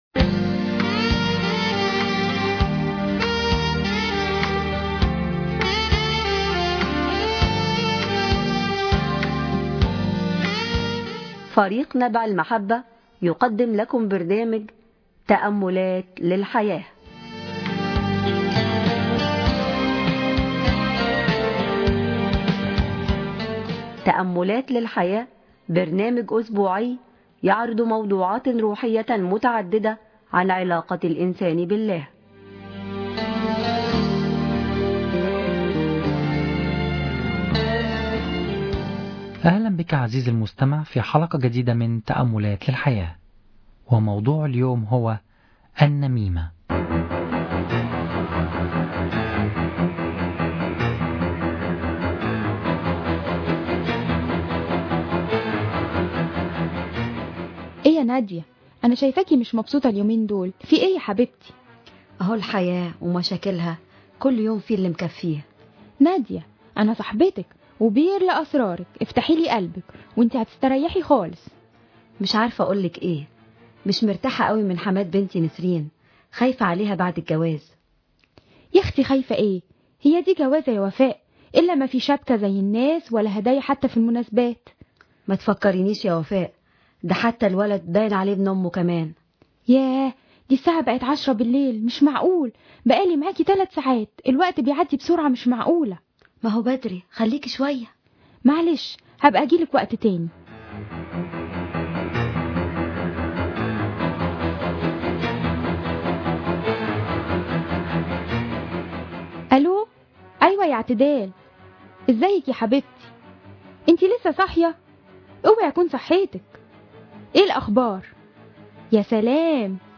دراما عربية gموضوعات روحية uن علاقة الانسان بالله